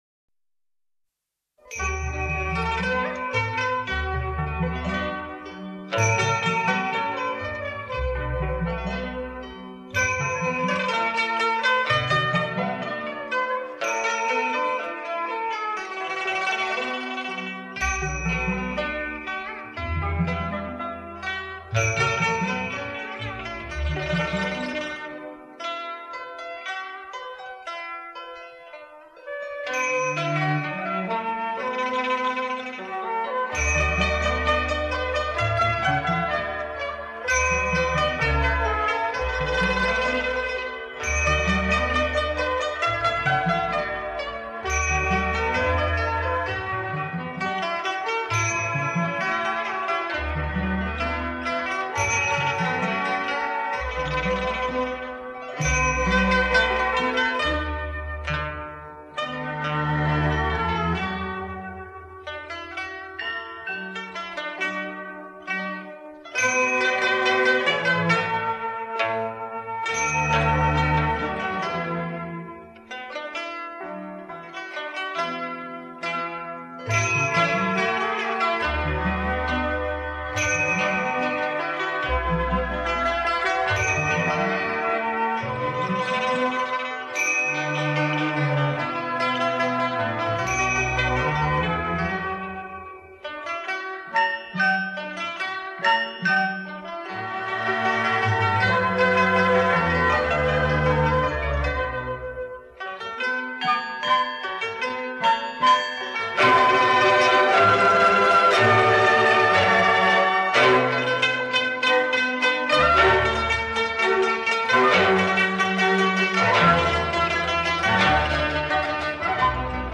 没办法，老版本～没有高音质了～不过很经典～送大家，让大家先听听～